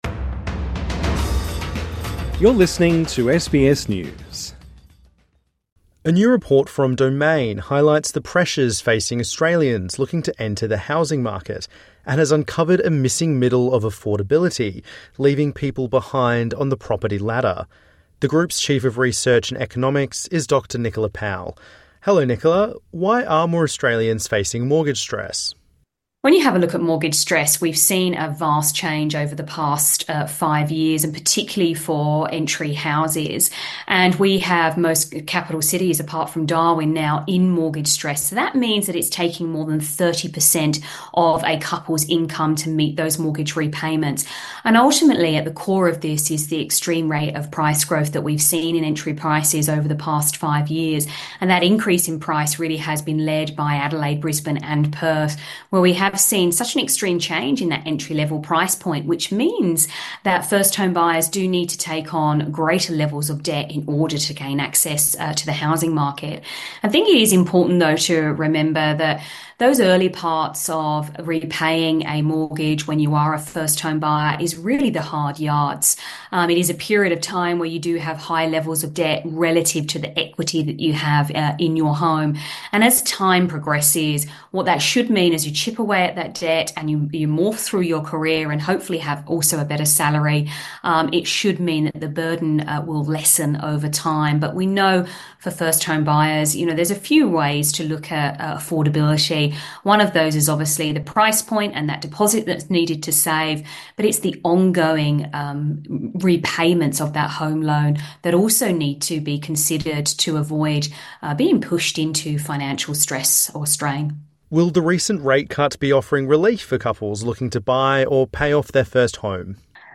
INTERVIEW: Australians are struggling to pay off their mortgage, let alone save a deposit